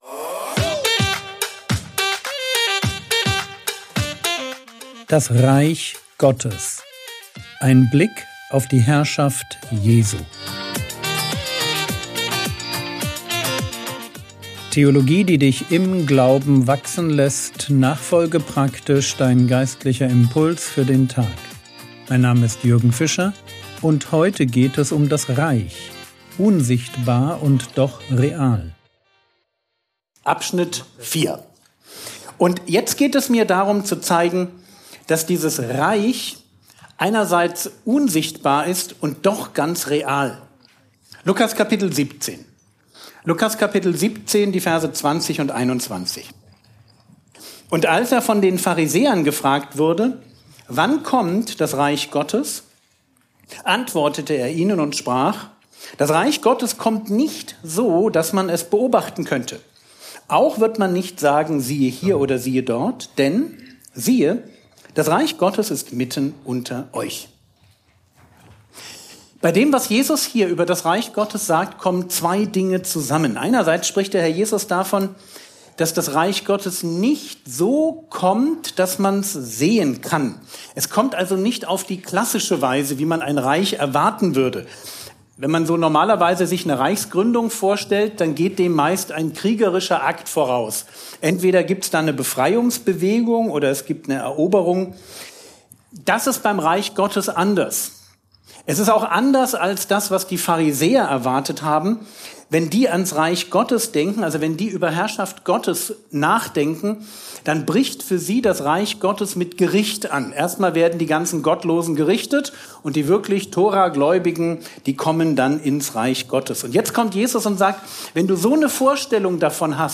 Vortrag Paderborn